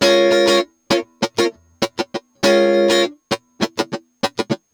100FUNKY02-R.wav